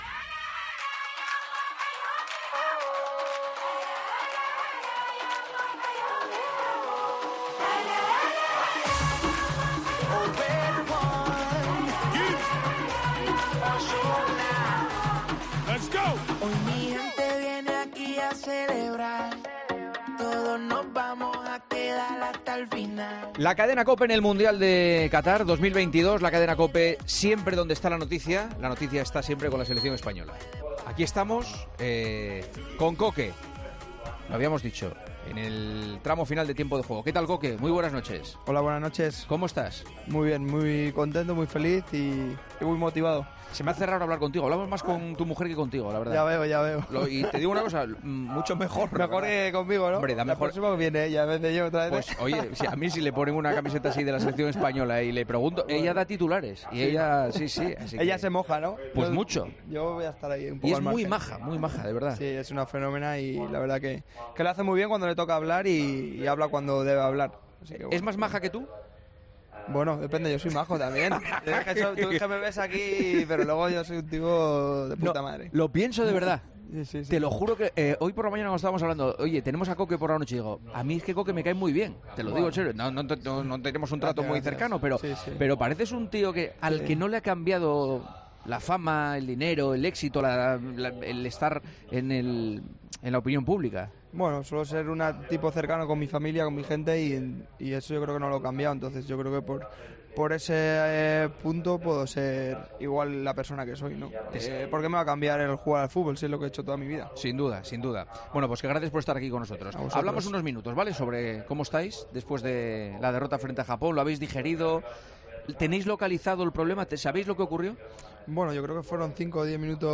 AUDIO: Juanma Castaño entrevista a Koke en Tiempo de Juego, dos días antes de enfrentarse a Marruecos en los cuartos de final de Mundial de Qatar.